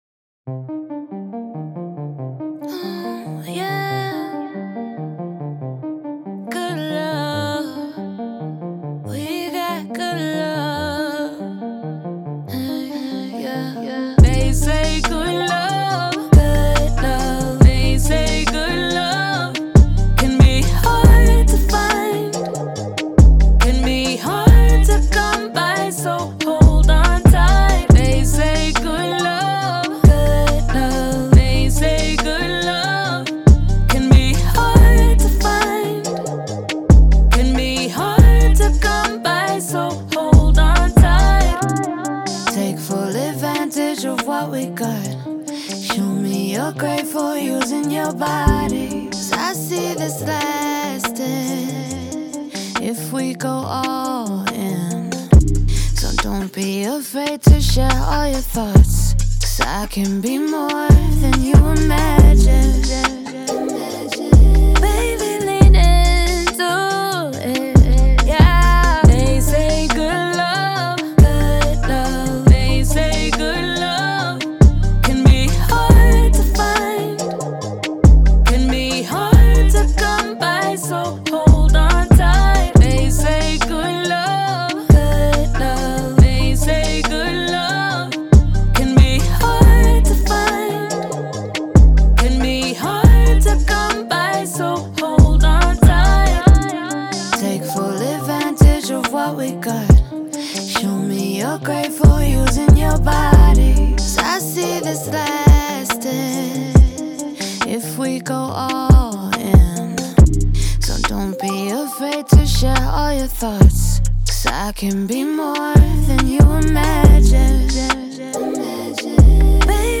R&B, Hip Hop
C min